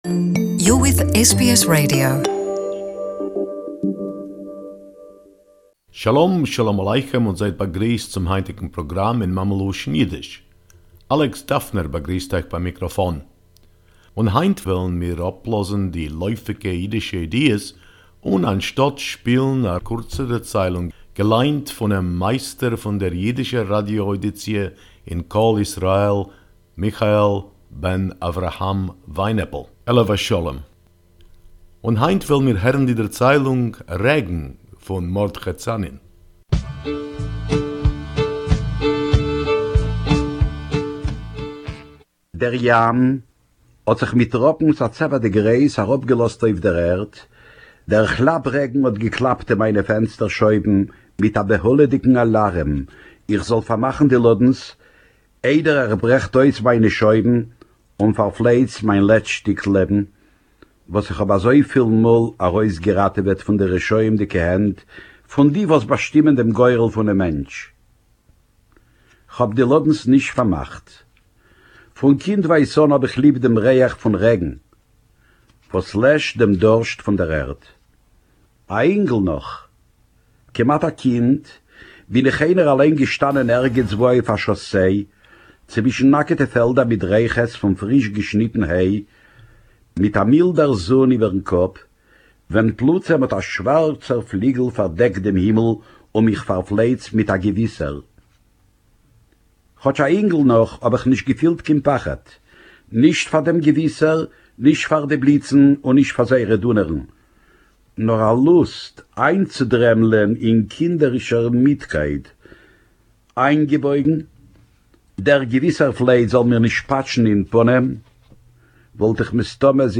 Yiddish story: Rain